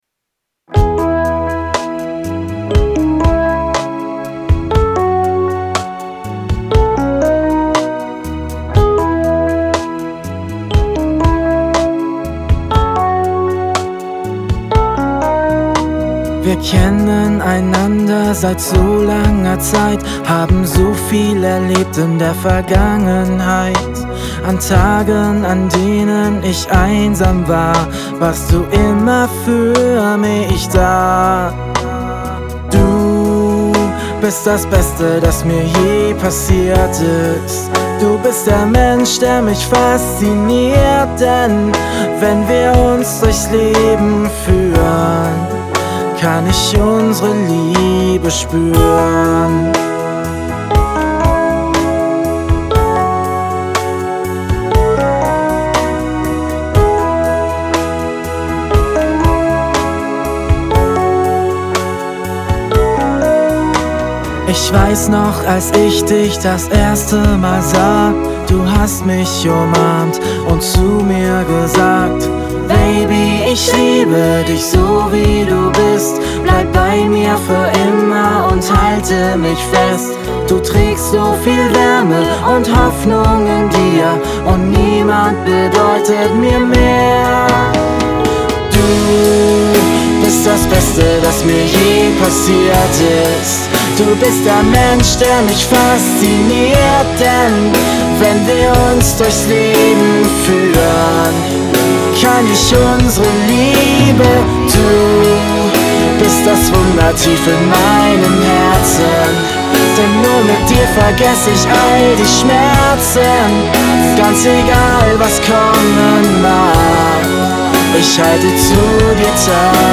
A romantic ballad
Duo
Ballade
romantisch